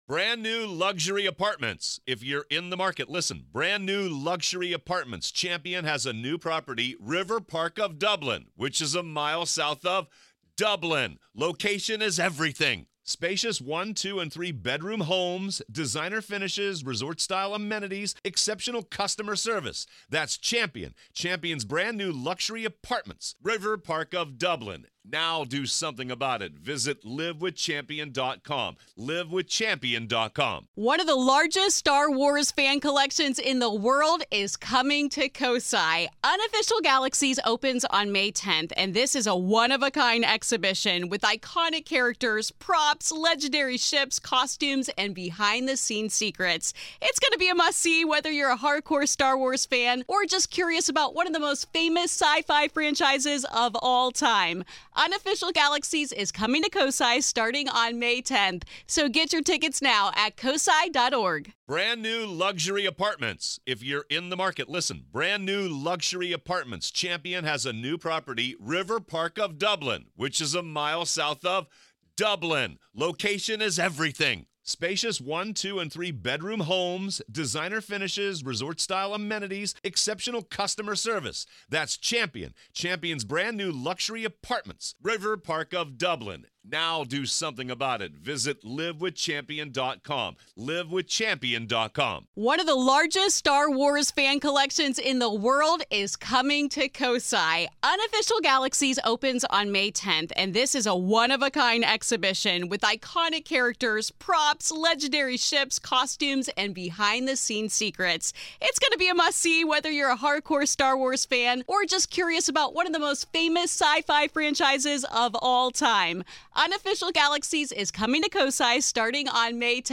Are spirits simply lingering memories, or do they choose to reveal themselves to certain people for a reason? Through compelling interviews, bone-chilling accounts, and haunting revelations, we explore why the line between hunter and hunted isn’t as clear as you might think.